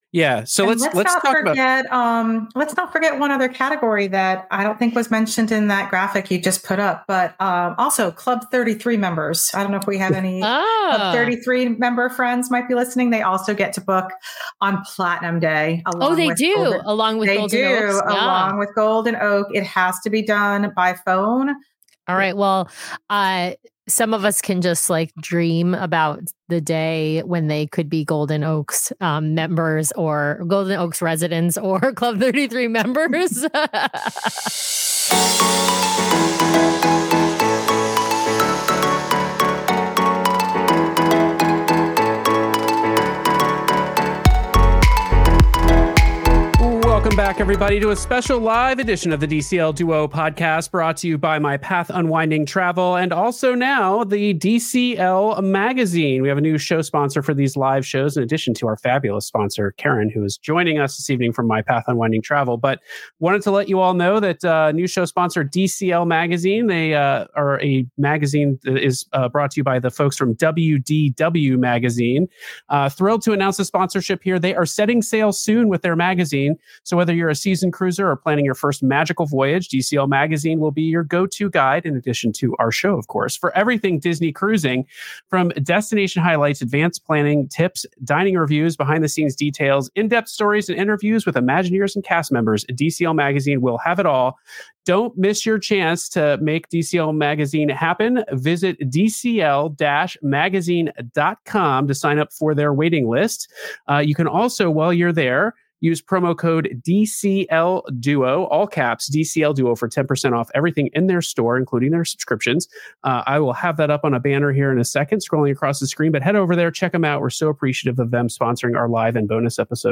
Ep. 459 - Live Bonus Show - Chart Your Destiny: Destiny Itineraries, Pricing and Booking Information